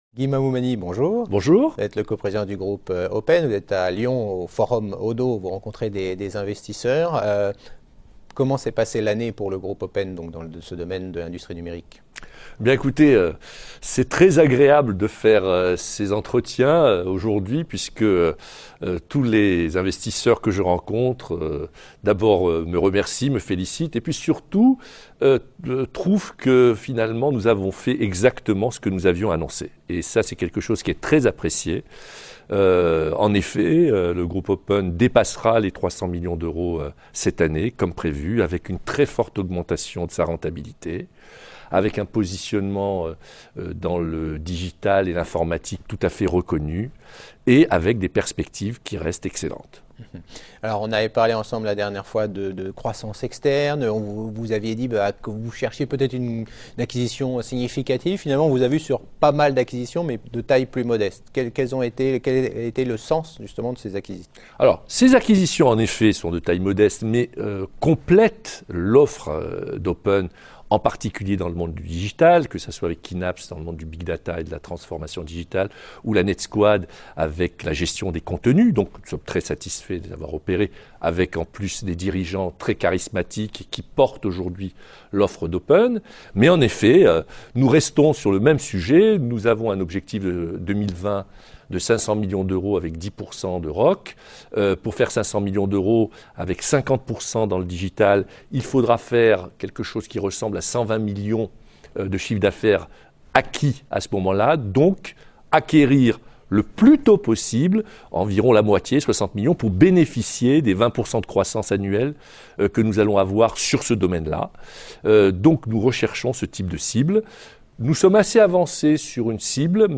Rencontres avec les dirigeants au Oddo Forum de Lyon du 5 et 6 janvier 2017
La Web TV a rencontré les dirigeants d’entreprises cotées au Oddo Forum de Lyon du 5 et 6 janvier 2017.